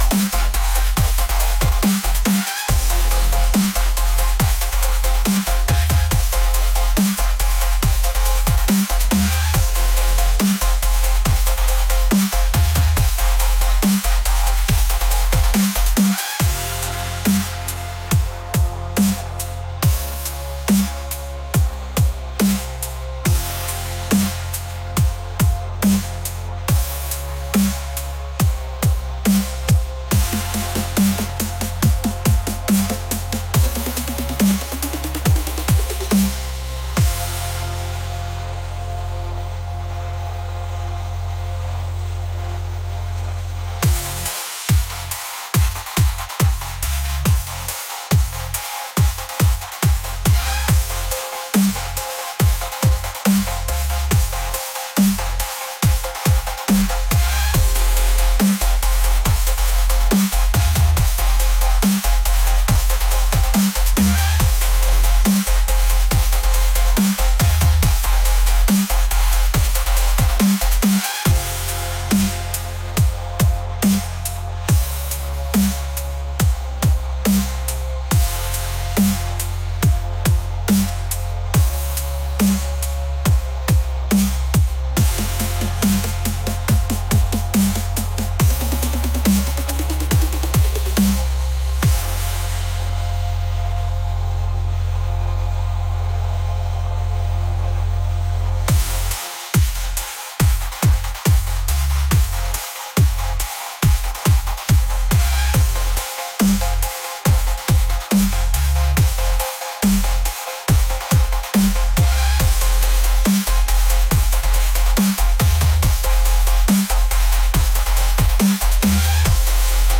energetic | intense